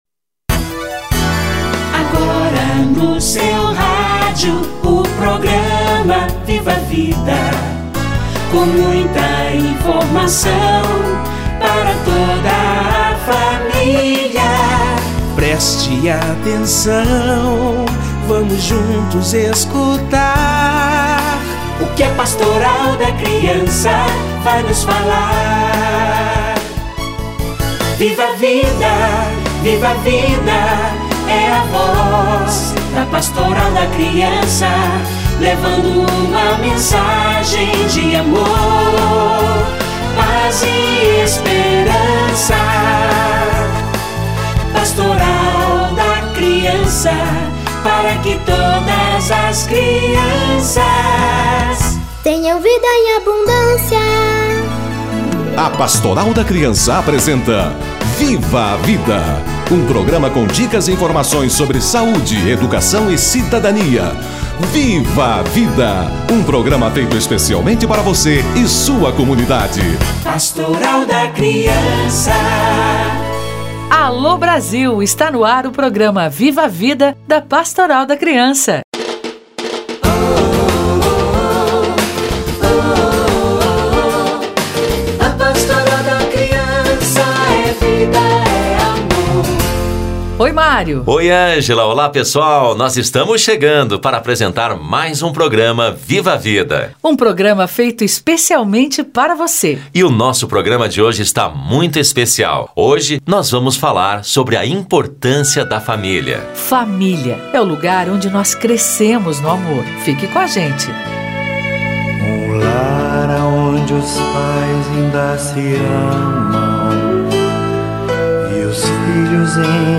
Importância da família - Entrevista